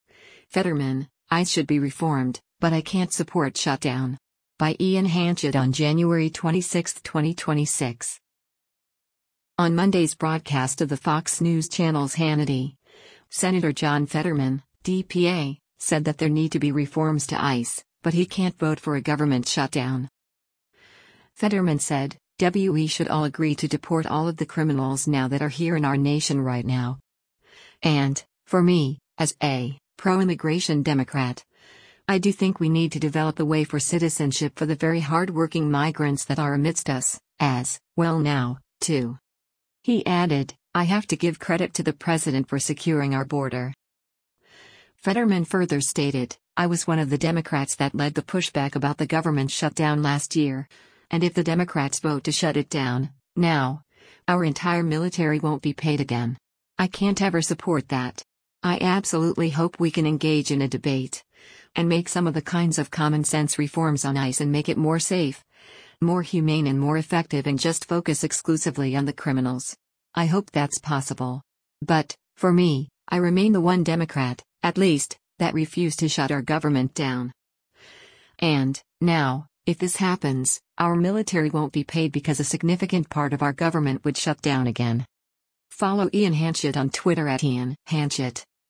On Monday’s broadcast of the Fox News Channel’s “Hannity,” Sen. John Fetterman (D-PA) said that there need to be reforms to ICE, but he can’t vote for a government shutdown.